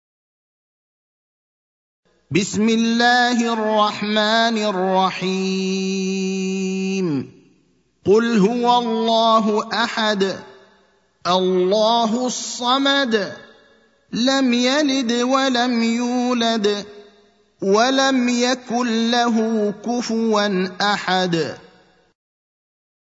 المكان: المسجد النبوي الشيخ: فضيلة الشيخ إبراهيم الأخضر فضيلة الشيخ إبراهيم الأخضر الإخلاص (112)